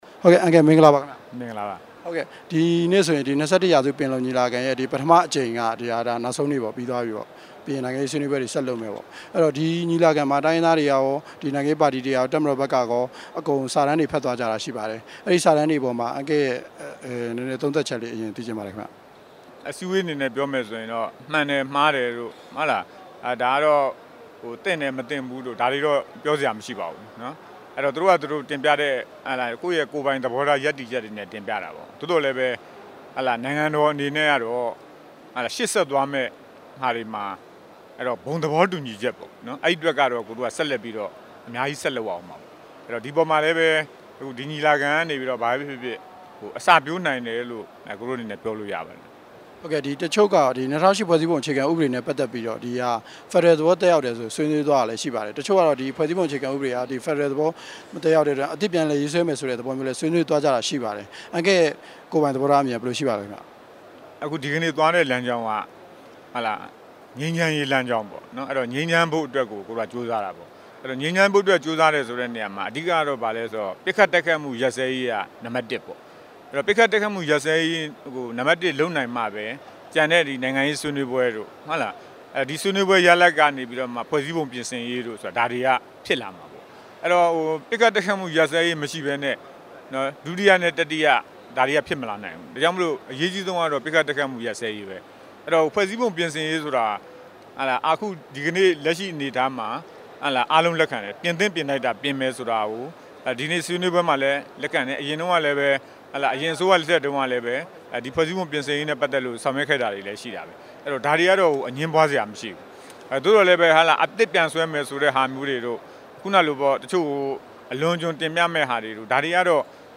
အငြိမ်းစား ဒုတိယ ဗိုလ်ချုပ်ကြီး မြင့်စိုးနဲ့ တွေ့ဆုံမေးမြန်းချက်